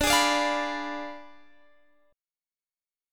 Listen to Ddim7 strummed